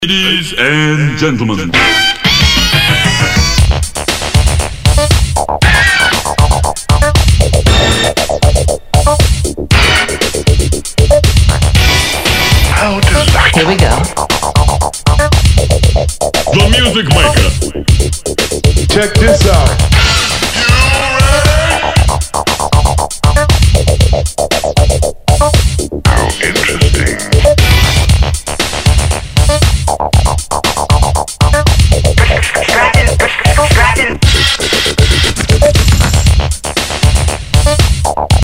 Tag       HIP HOP UNDERGROUND